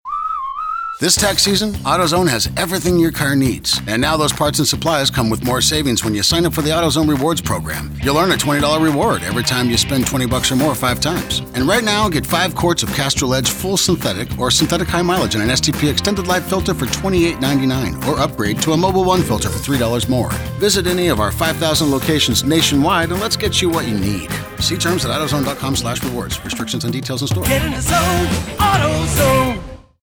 Consider the AutoZone commercial. It opens with their sonic logo, a whistling sound that mirrors its slogan. Utilizing music throughout, the ad ends with its signature “Get in the zone…AutoZone!” jingle.